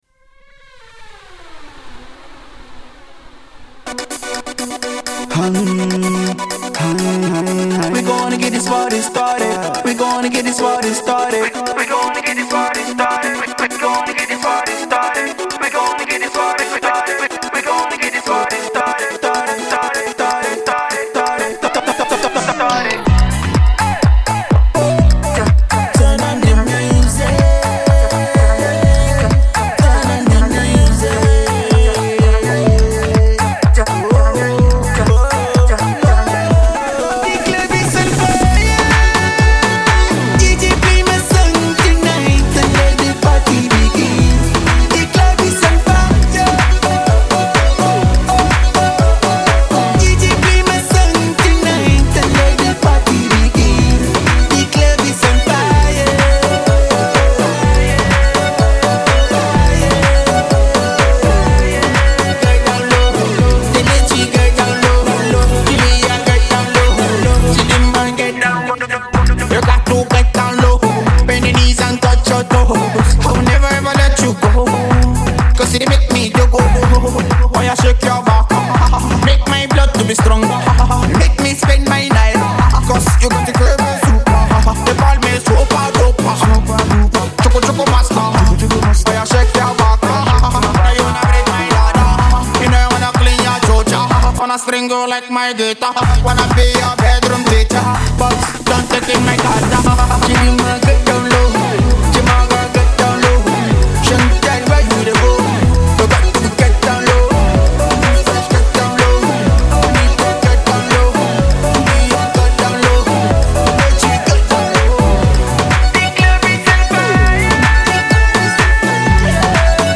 Afro-Dance tune